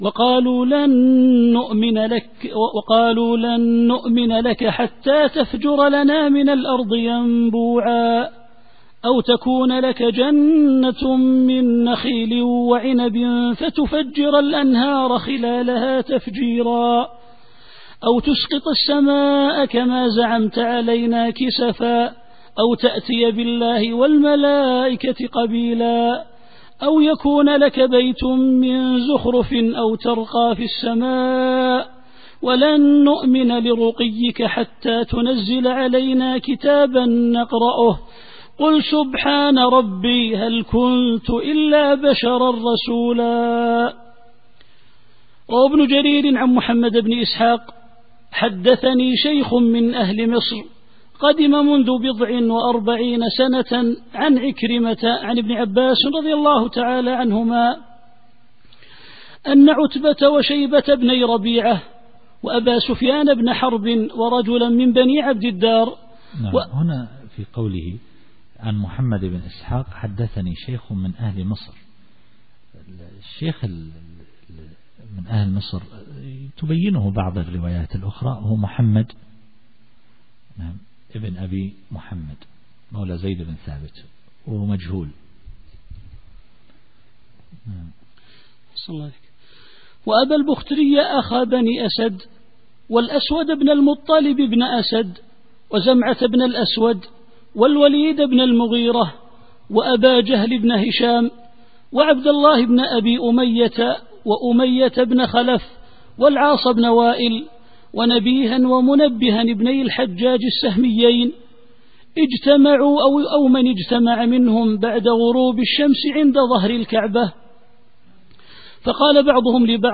التفسير الصوتي [الإسراء / 90]